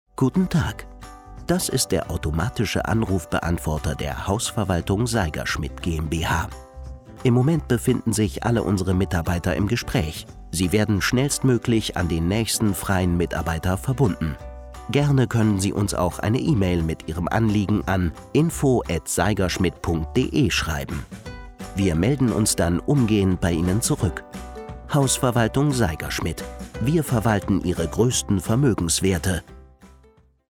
markant, sehr variabel
Jung (18-30)
Wait Loop (Warteschleife)